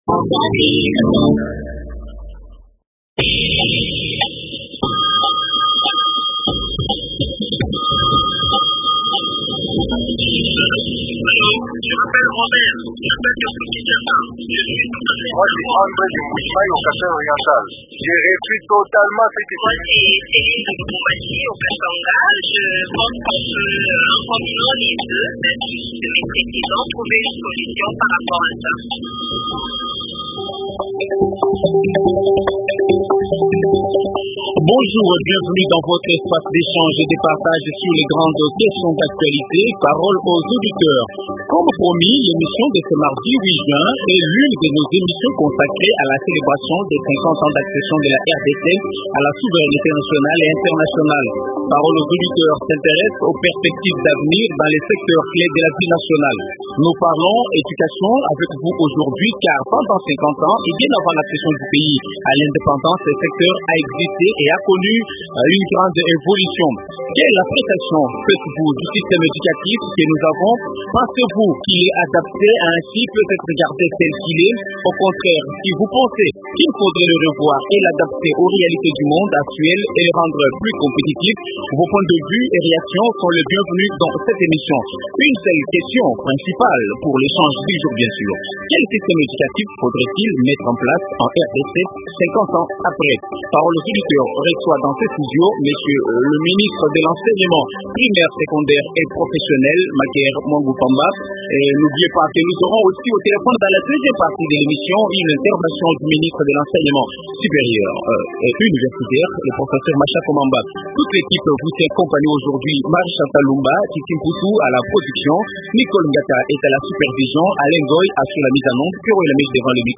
Invité : Maker Muangu Famba, ministre de l’Enseignement primaire, secondaire et professionnel.